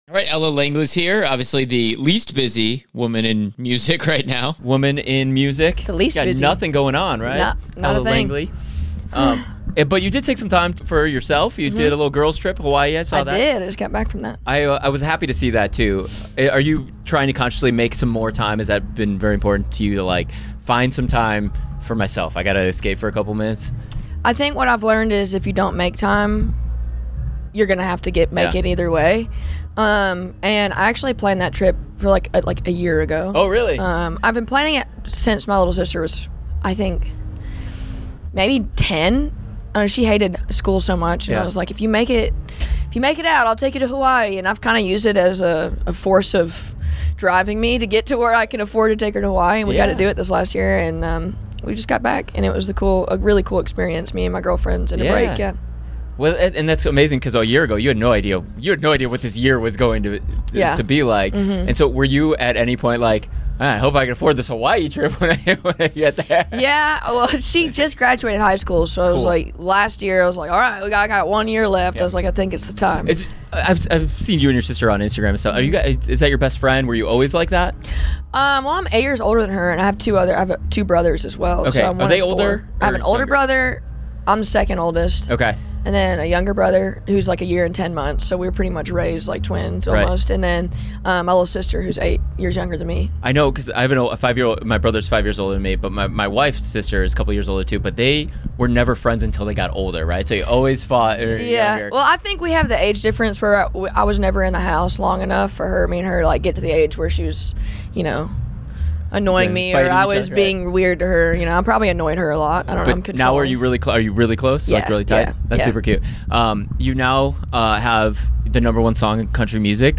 Interview - WIndy City Smokeout 2025